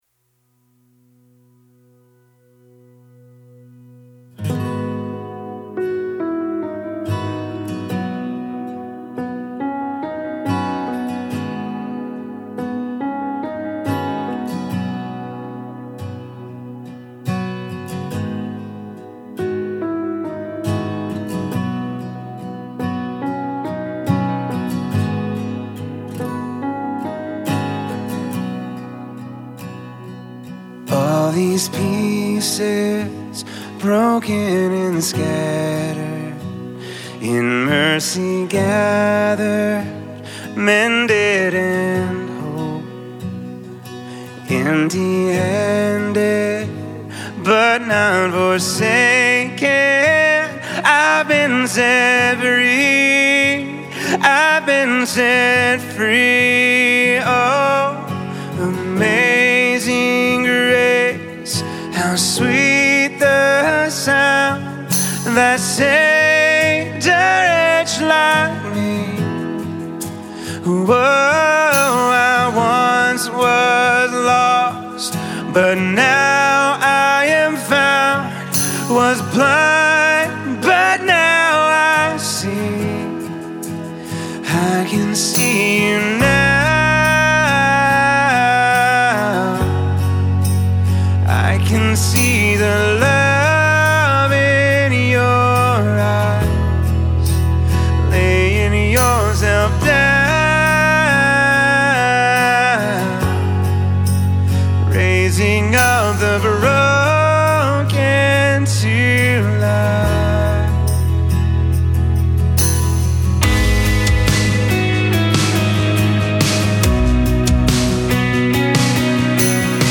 Full arrangement demo
• Keys: B, G, and A
• Tempo: 70 bpm, 4/4 time
STYLE: ORGANIC
• Drums
• Percussion
• Acoustic Guitar
• Electric Guitar (2 tracks)
• Bass
• Pads
• BGV’s